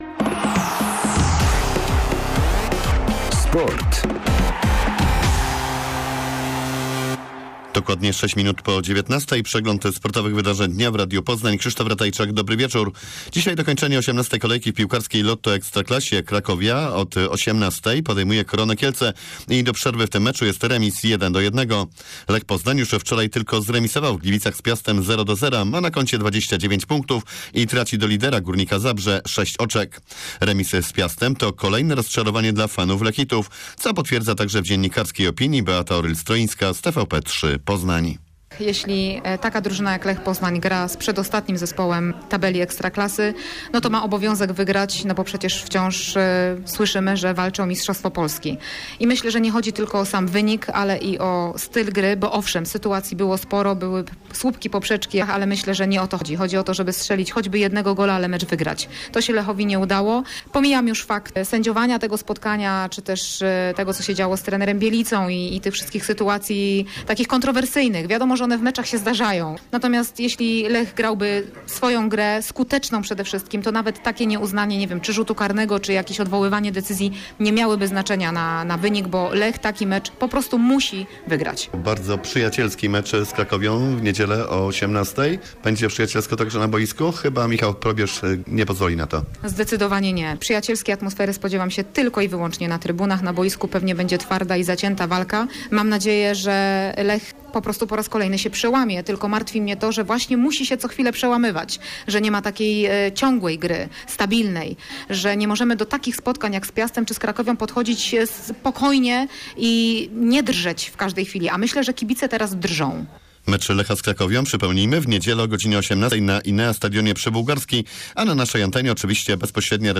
04.12 serwis sportowy godz. 19:05